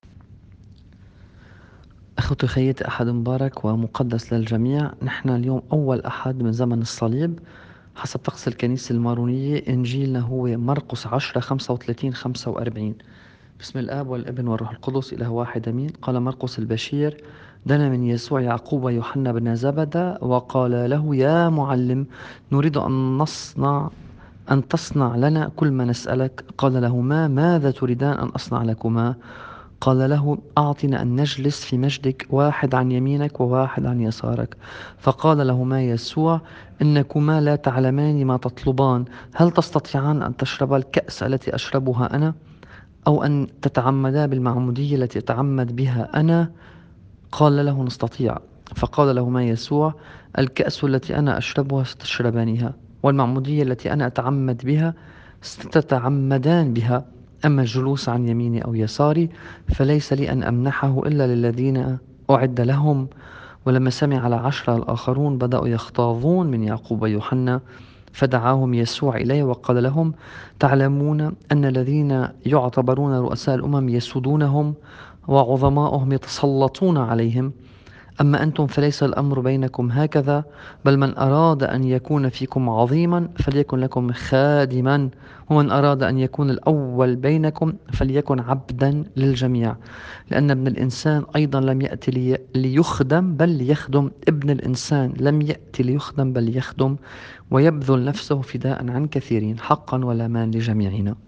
الإنجيل بحسب التقويم الماروني :